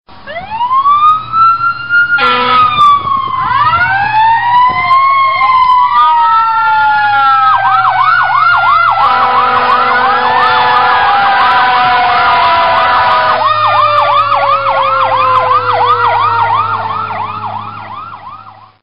Sirens, Ambulans siren, Android